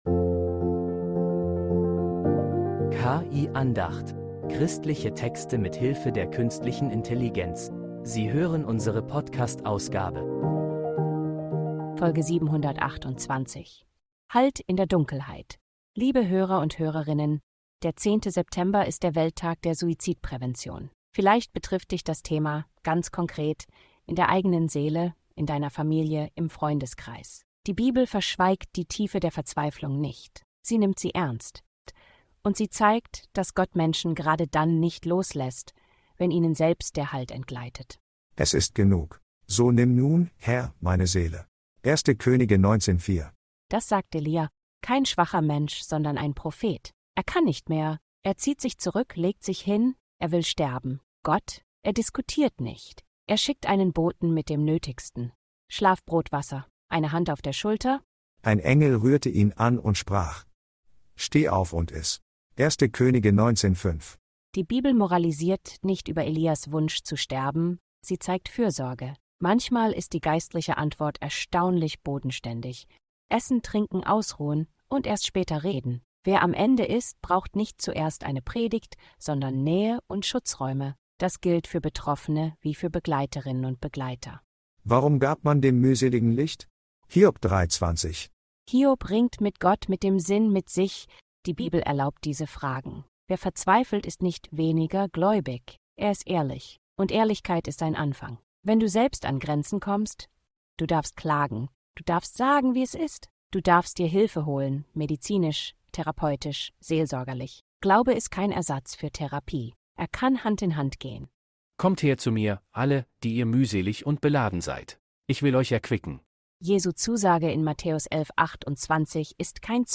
Christliche Texte mit Hilfe der Künstlichen Intelligenz